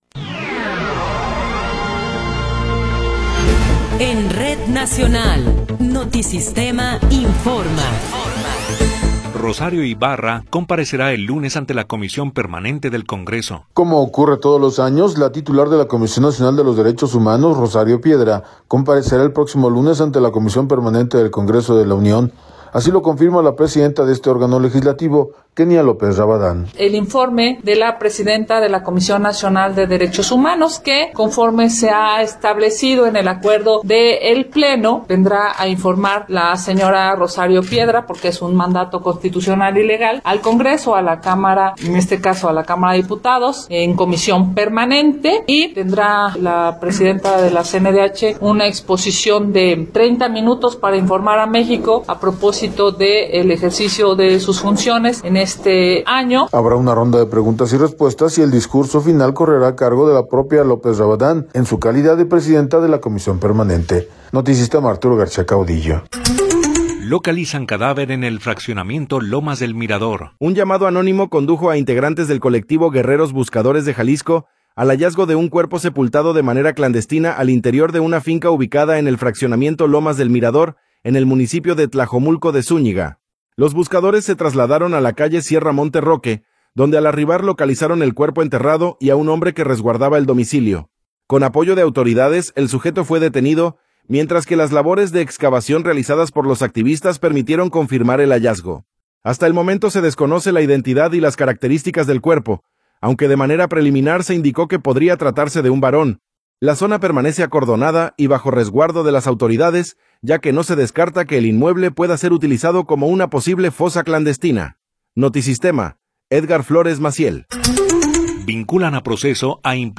Noticiero 19 hrs. – 20 de Enero de 2026